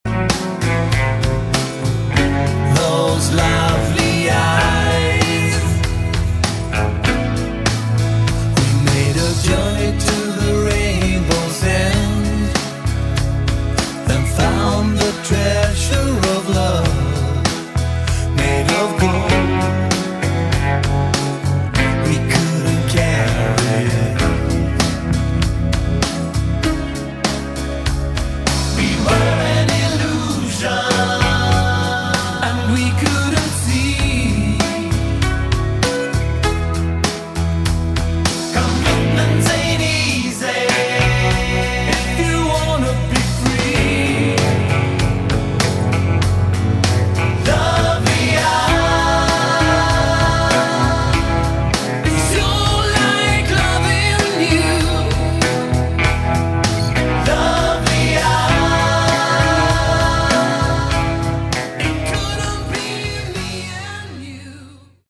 Category: Hard Rock
lead vocals
keyboards
bass
lead guitar
drums